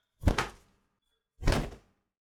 Cloth Shirt Whip Sound
household
Cloth Shirt Whip